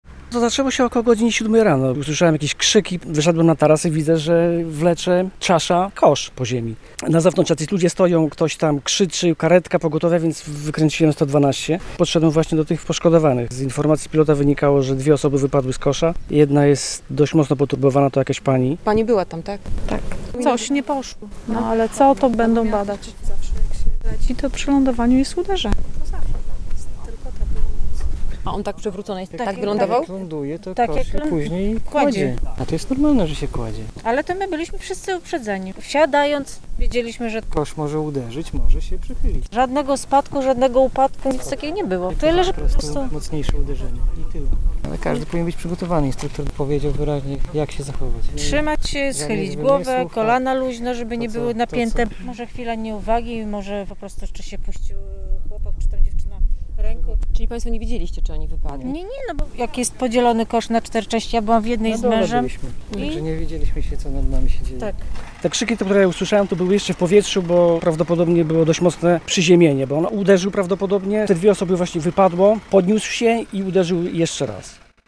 Wypadek podczas lądowania balonu - relacja